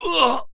文件 文件历史 文件用途 全域文件用途 Enjo_dmg_01_2.ogg （Ogg Vorbis声音文件，长度0.5秒，154 kbps，文件大小：10 KB） 源地址:地下城与勇士游戏语音 文件历史 点击某个日期/时间查看对应时刻的文件。